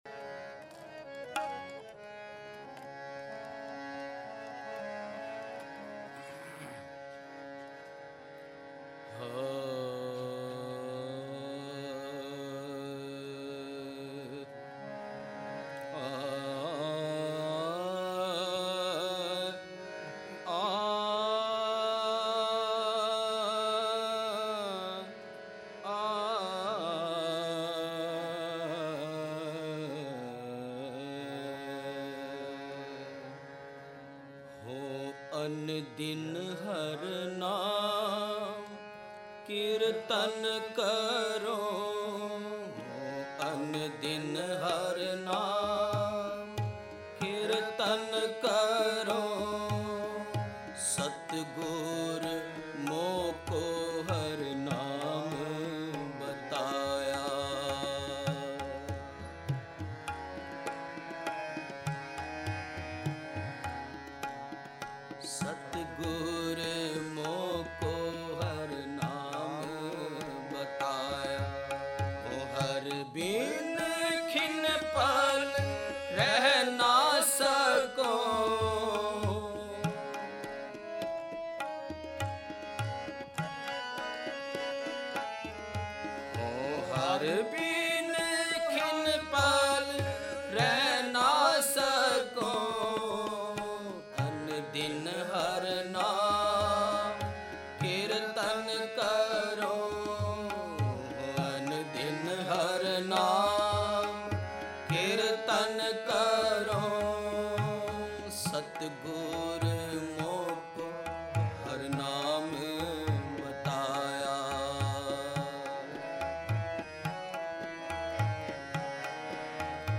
Genre: Shabad Gurbani Kirtan Album Info
Raag Asavari